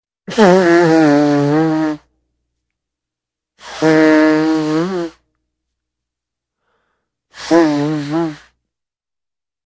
Звуки сморкания
Звук сморкания носом в платок